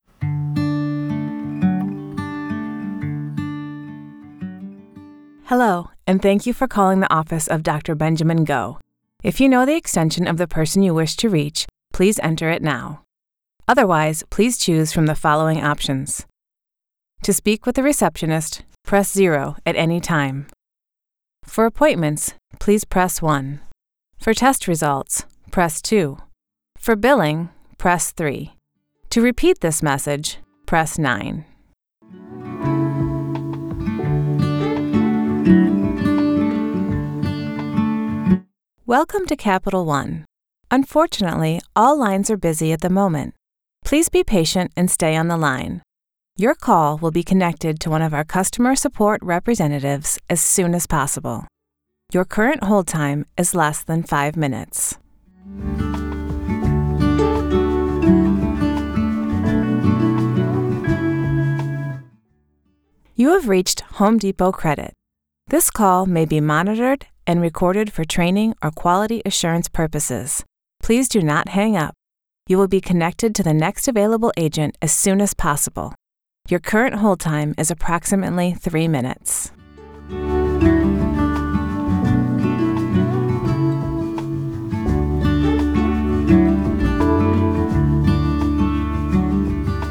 Demos Commercials Download Narration Download Political Download IVR/On Hold Messaging Download
ivr-demo.wav